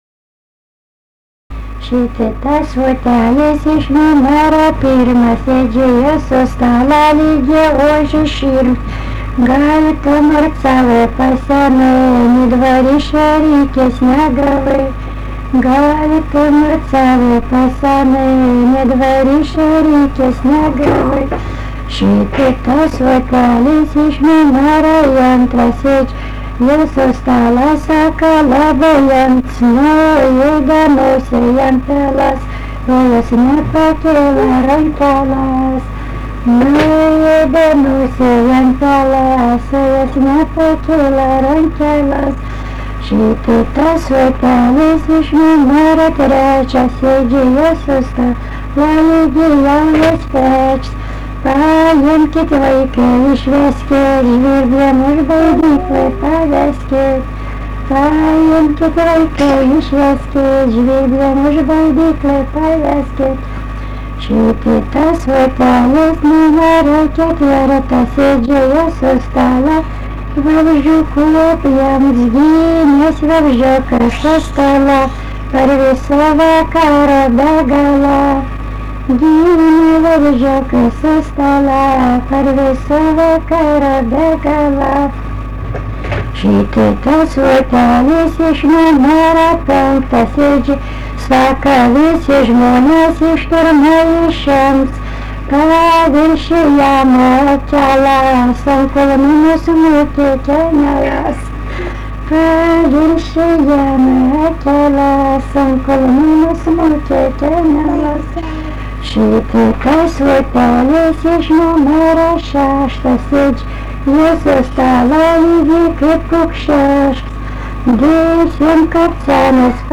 daina, vestuvių
Jurgėnai
vokalinis